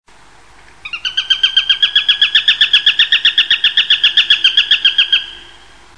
Accipiter_nisus_converted.mp3